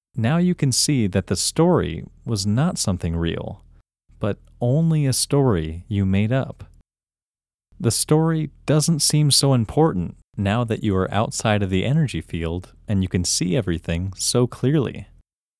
OUT – English Male 19